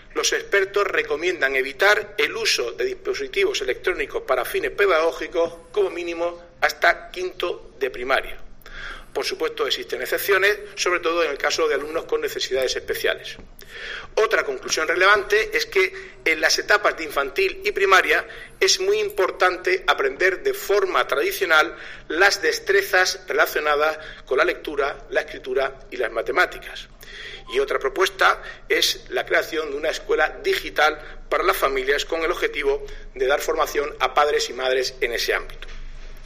Marcos Ortuño, portavoz del Gobierno de Murcia